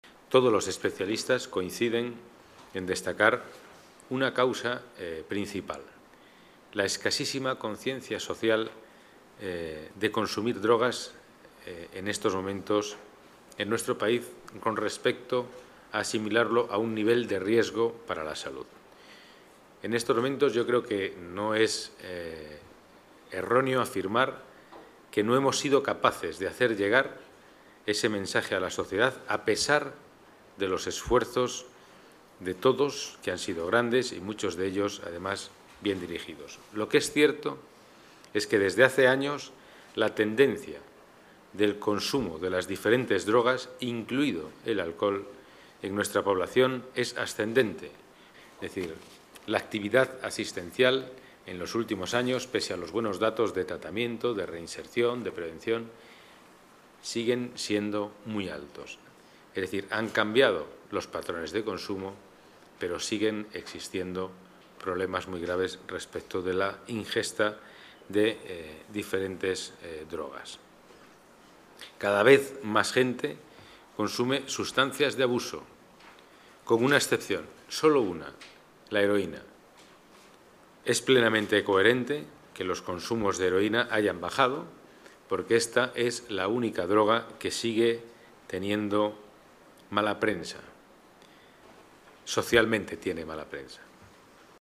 Nueva ventana:El delegado de Seguridad y Movilidad, Pedro Calvo, explica los cambios en el consumo de drogas con los resultados del balance del último cuatrienio